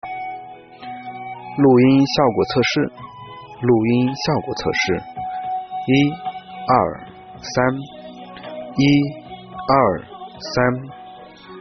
收音麦克风最重要的是收音效果，这款塞宾智能无线麦克风采用的全指向高保真咪头，在效果上能够让声音真实清澈，在嘈杂的环境中，也能够有出色的表现。
通过这段真实的语音测试效果，会听到录制时的背景音乐，不仅凸出了人声，还保留了录制的声音细节。